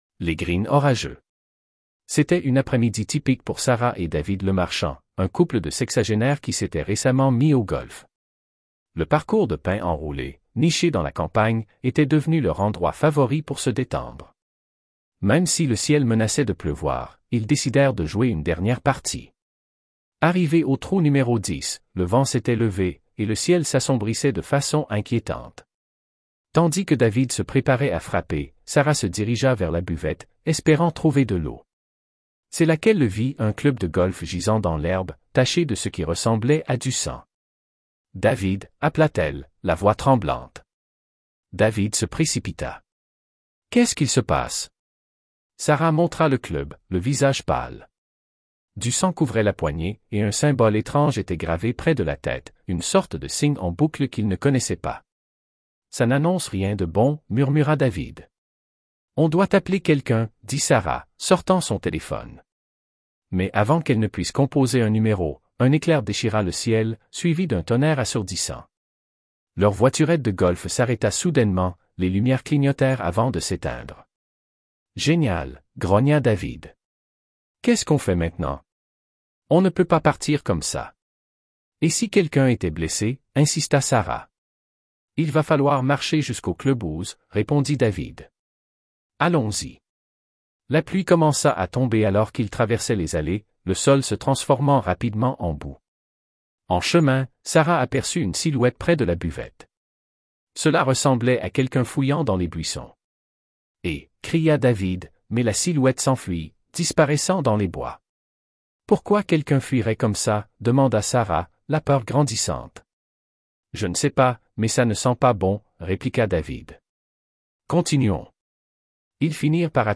Merci IA